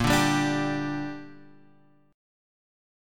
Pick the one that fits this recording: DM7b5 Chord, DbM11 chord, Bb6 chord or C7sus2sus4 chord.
Bb6 chord